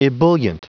Prononciation audio / Fichier audio de EBULLIENT en anglais
Prononciation du mot : ebullient